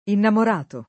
innamorato [ innamor # to ]